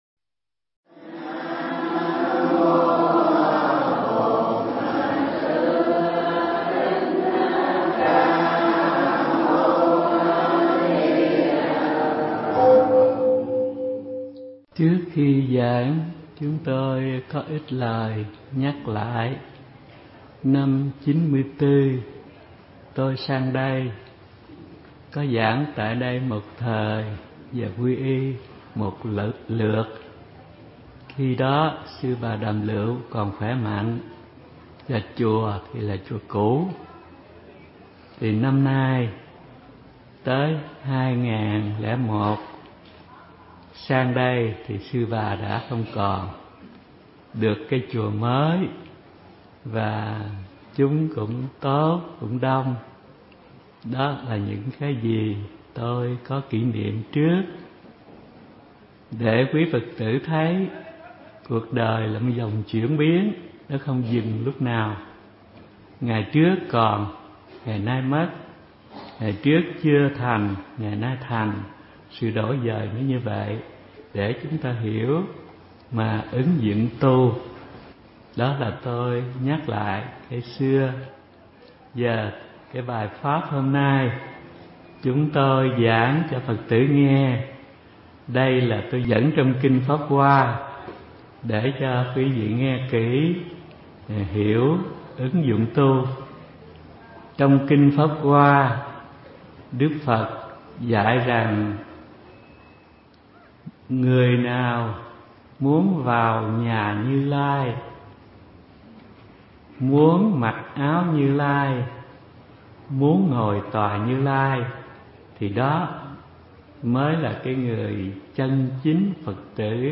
Nghe mp3 thuyết pháp Bi Nhẫn Trí – hòa thượng Thích Thanh Từ